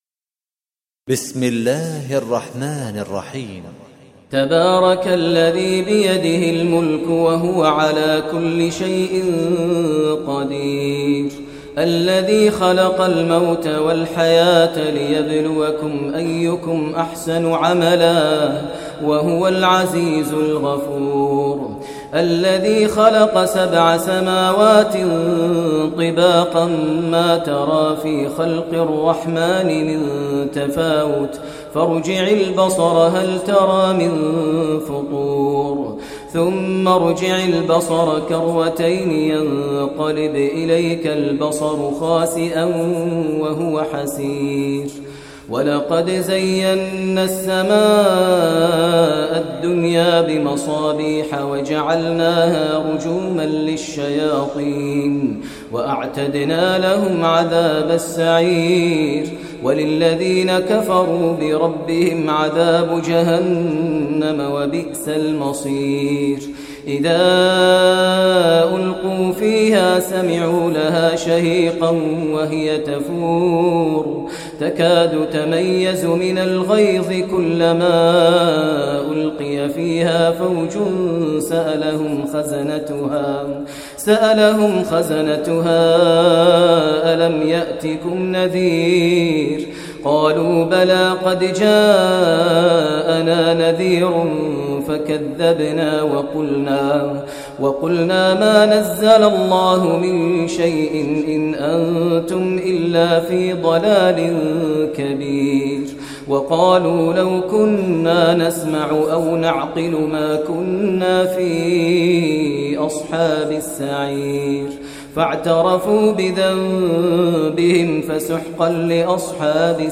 Surah Mulk Recitation by Sheikh Maher al Mueaqly
Surah Mulk, listen online mp3 tilawat / recitation in Arabic in the voice of Sheikh Maher al Mueaqly.